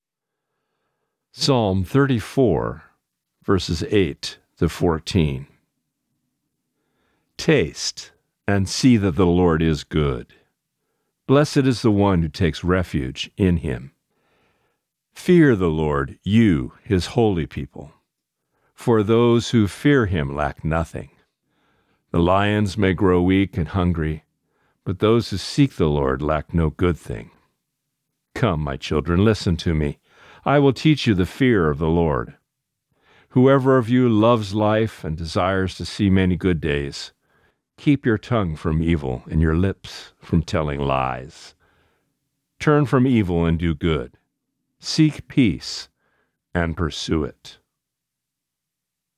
Reading: Psalm 34:8-14